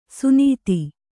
♪ sunīti